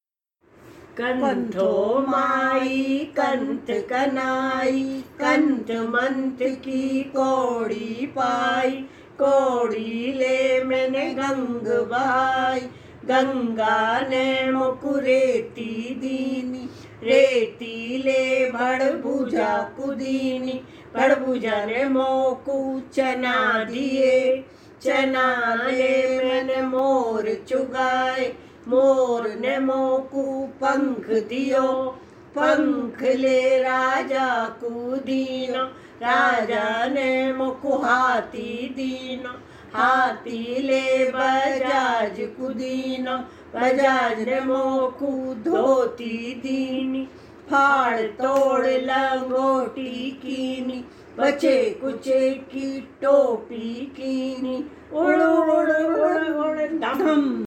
Una canción divertida en braj bhasha…
Balgeet (Balada)